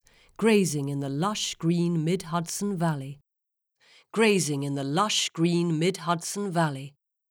Female voice quality - equalisation advice appreciated
Here’s the latest test recording, with laptop moved and working very slightly further back.
And she is a fist-width from the mic so perhaps I’ll suggest either a bit more distance or a slight angle.
There is still noticeable (bassy) reverb from the room.